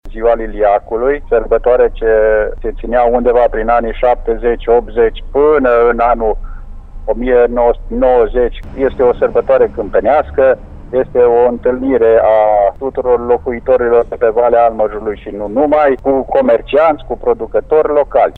Este vorba despre „Sărbătoarea Liliacului”, eveniment care adună pe valea morilor de apă toți locuitorii din zonă. Primarul comunei Rudăria, Mihai Otiman.